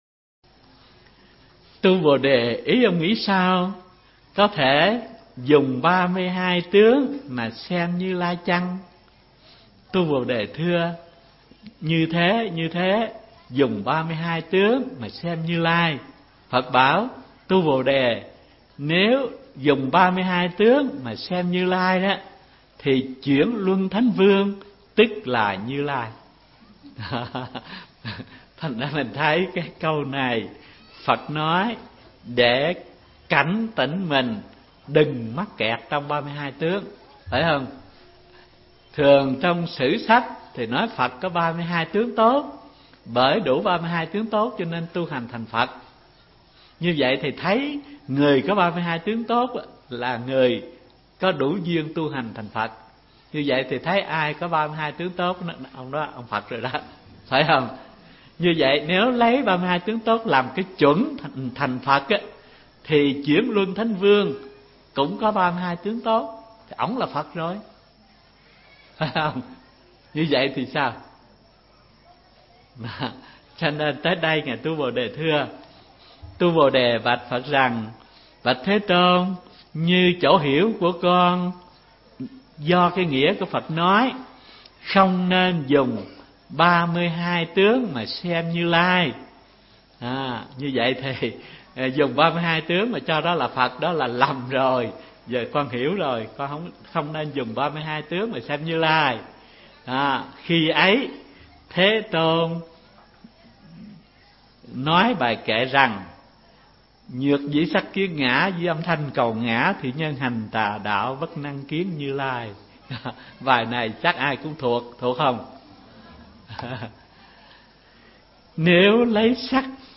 Mp3 Pháp Âm Giảng Kinh Kim Cang 10 – Hòa Thượng Thích Thanh Từ